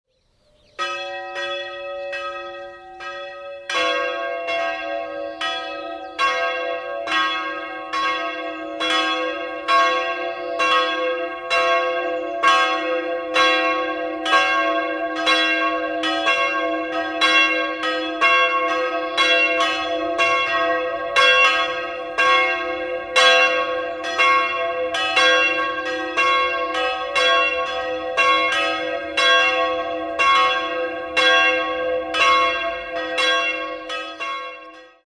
Jahrhundert in den barocken Neubau des Langhauses integriert wurde. 2-stimmiges Geläute: cis''-dis'' Die kleinere Glocke wurde 1746 von Christian Victor Heroldt in Nürnberg gegossen, die größere im Jahr 1750 von Johann Silvius Kleeblatt in Amberg.